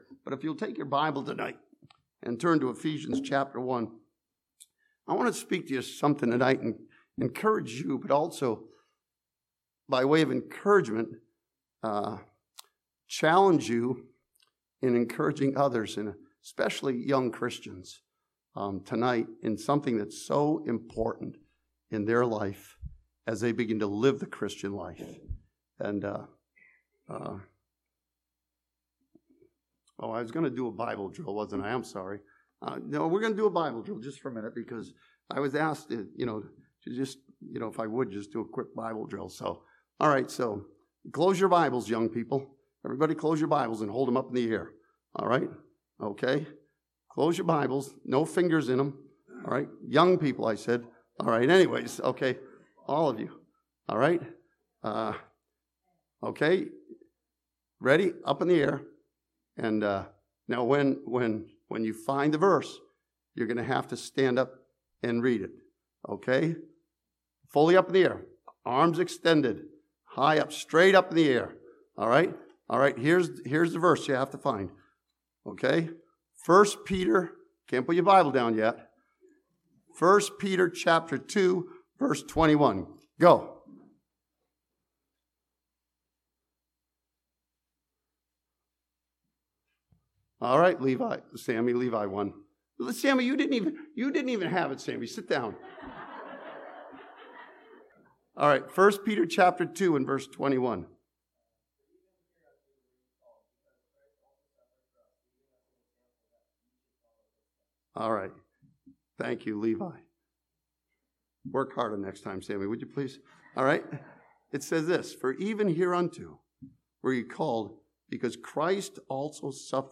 This sermon from Ephesians chapter one challenges the believer to walk and serve in the power of the Holy Spirit.